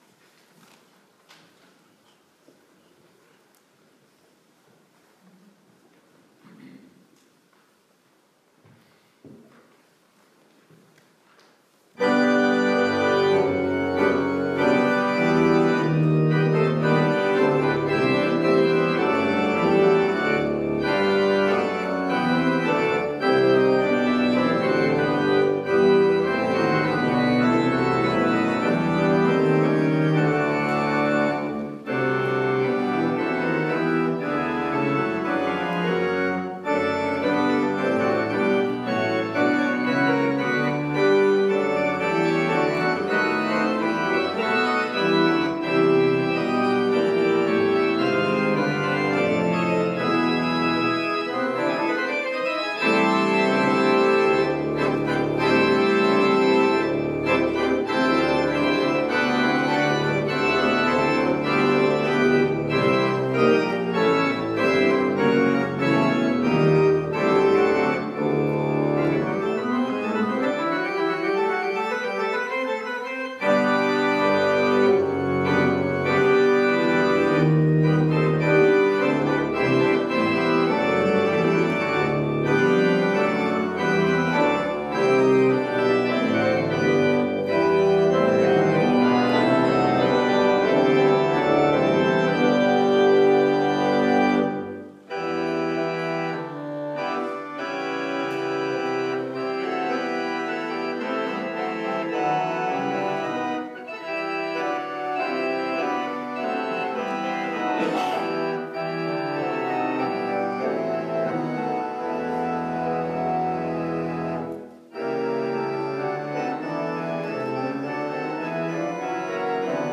Ljud och bild från evenemangen.